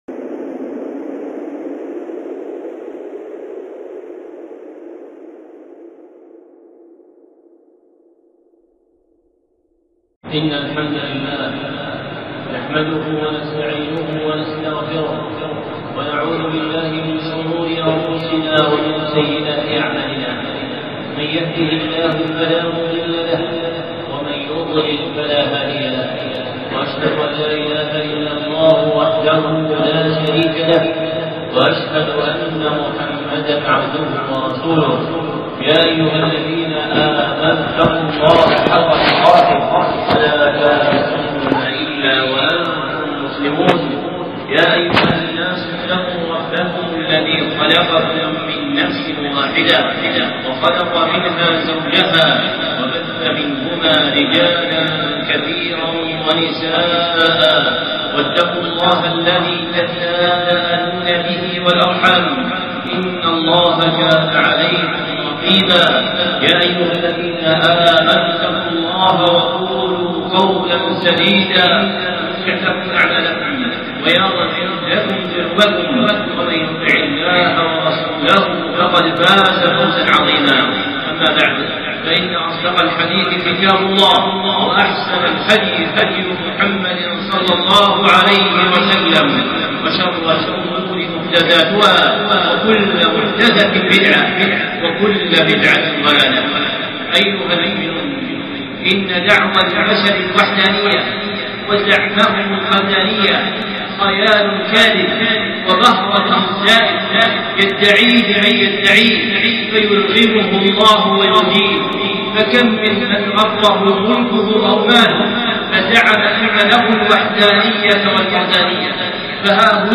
خطبة (معرفة الله)